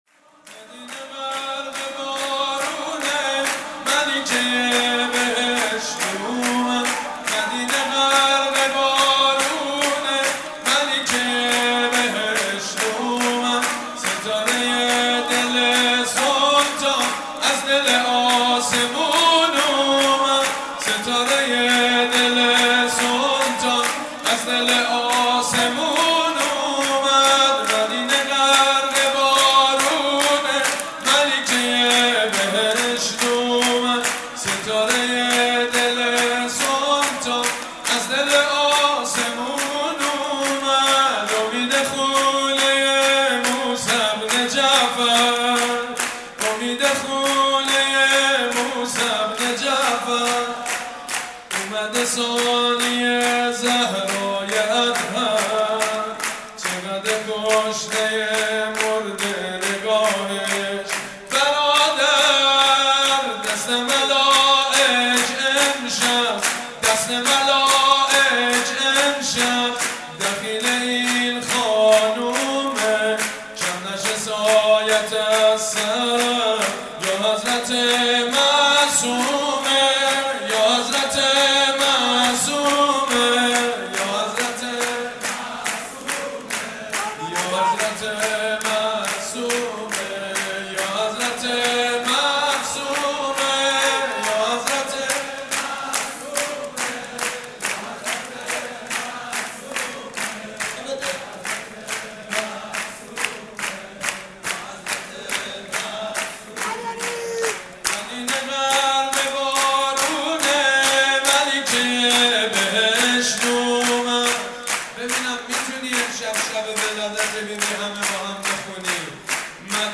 جشن میلاد حضرت معصومه(س) در هیئت رزمندگان اسلام قم